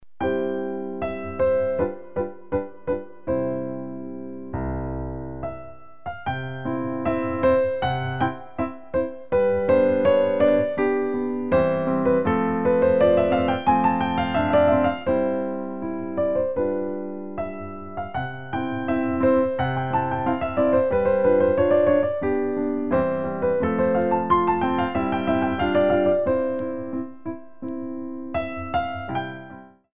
Piano Arrangements of Classical Compositions